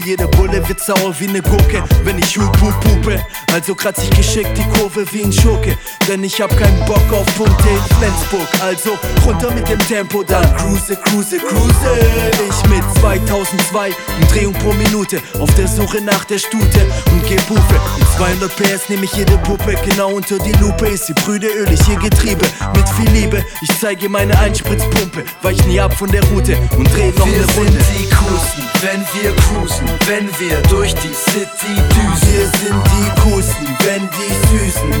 Жанр: Хип-Хоп / Рэп / Рок